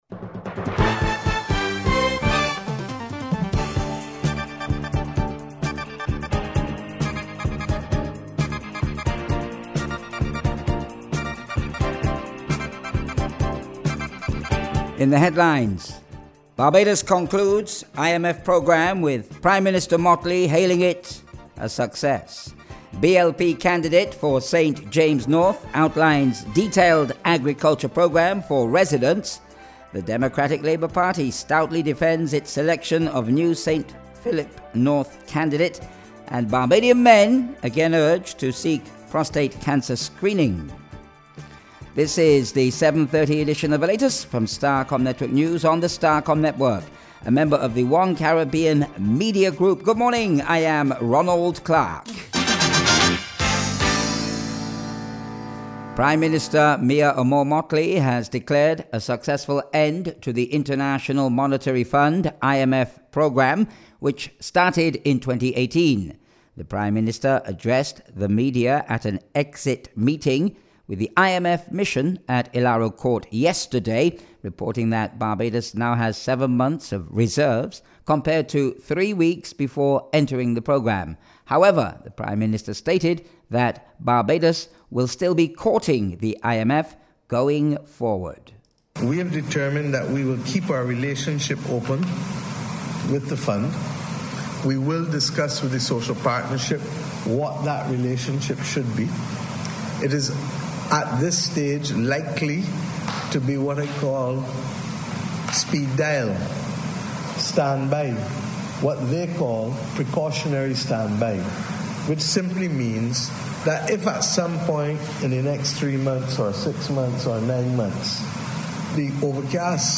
Acting Minister of the Environment and National Beautification, Corey Lane, today provided Starcom Network News with an update on the program.